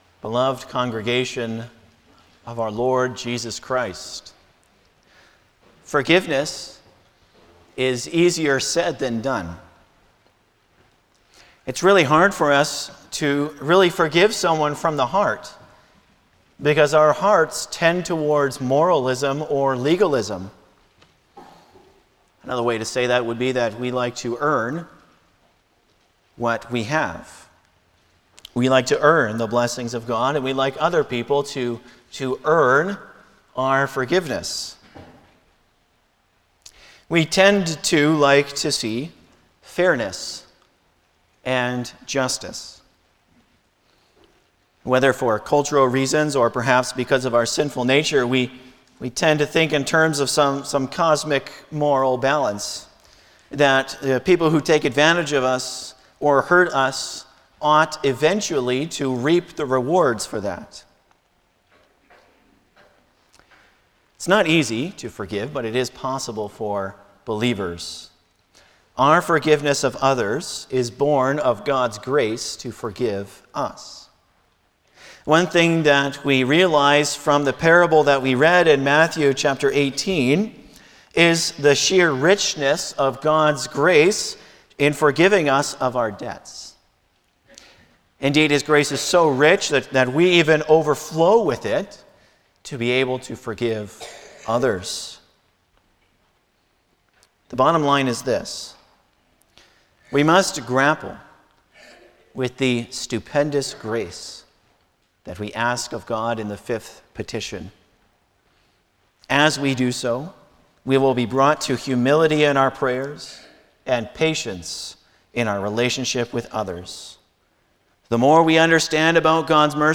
Passage: Lord’s Day 51 Service Type: Sunday afternoon
07-Sermon.mp3